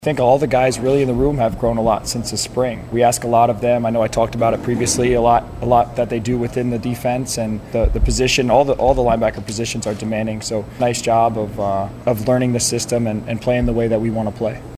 LINCOLN – Nebraska Football wrapped their final weekday practice of the second week of fall camp this morning, as a defensive emphasis was shown in the media pressers following the session.